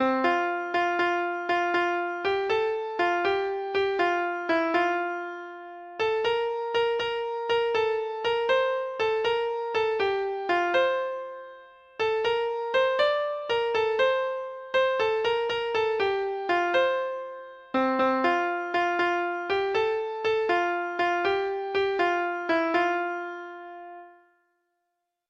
Free Sheet music for Treble Clef Instrument